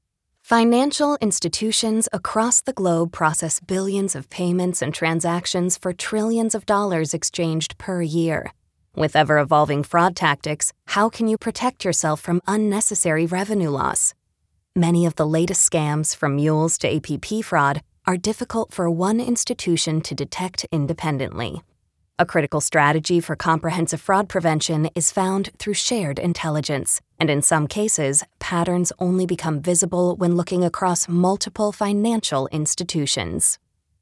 Narration
Documentaires
Microphone : Sennheiser 416, Rode NT-1, Apogee Hype Mic
Cabine : StudioBricks